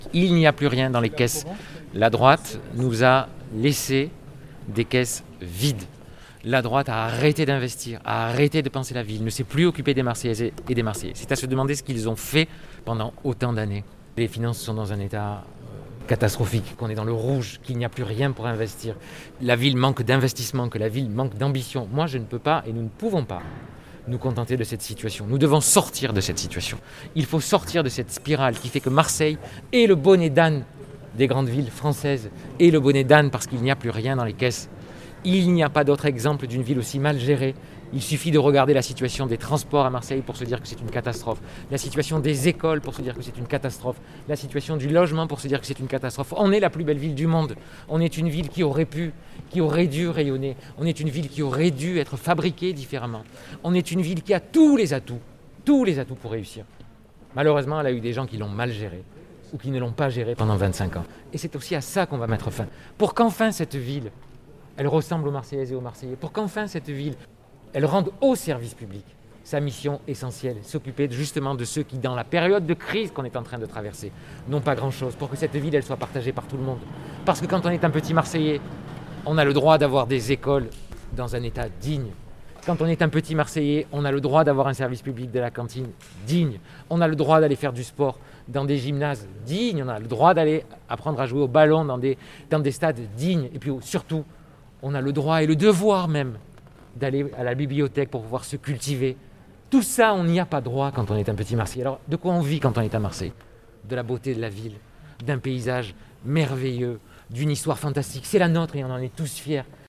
Entretien avec Benoît Payan